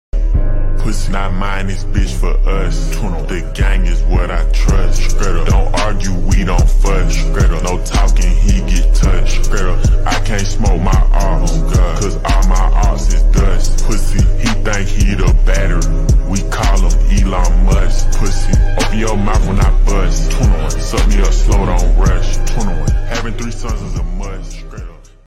shake, zooms, glitch, cc, outro, sound effects free download